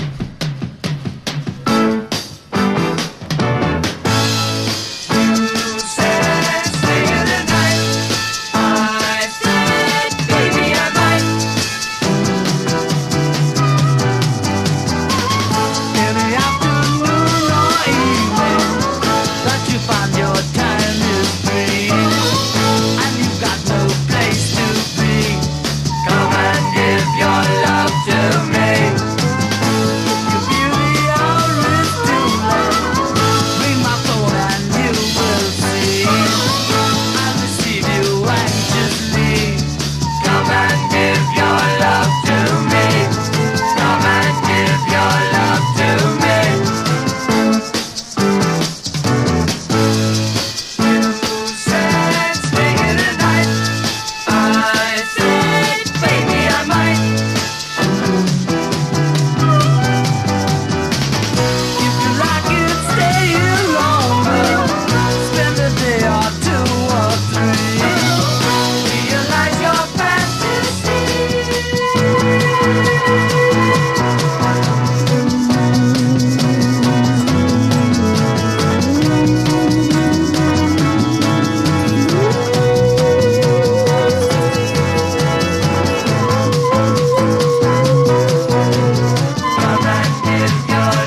柔らかくまどろんだコーラス/ハーモニーに溢れたシカゴ・ガレージ・ソフトロック！